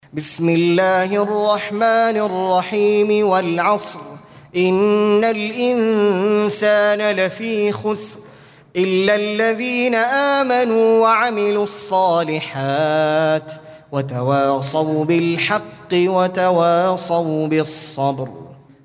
Audio Quran Tarteel Recitation
Surah Repeating تكرار السورة Download Surah حمّل السورة Reciting Murattalah Audio for 103. Surah Al-'Asr سورة العصر N.B *Surah Includes Al-Basmalah Reciters Sequents تتابع التلاوات Reciters Repeats تكرار التلاوات